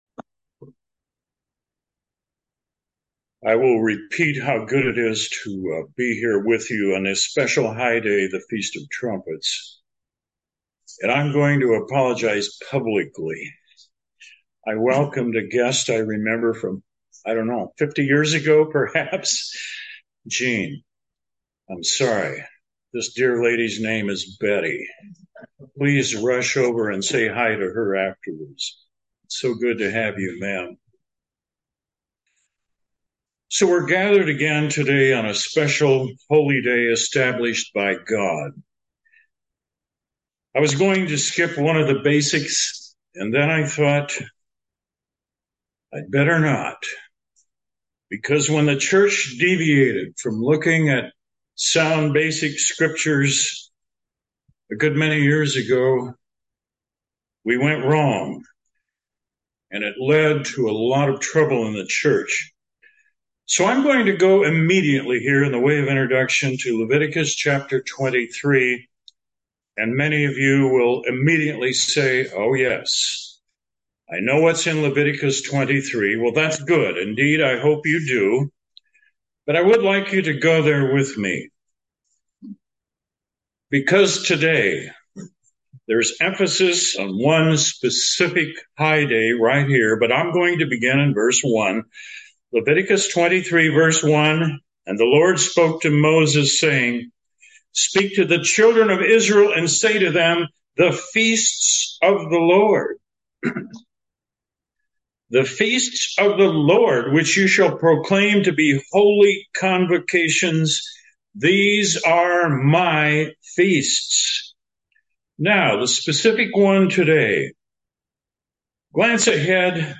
This sermon focuses on the time leading up to Christ's return when seven trumpets will sound. The seventh trumpet announces the return of Jesus Christ. It is a time of tragedy and triumph.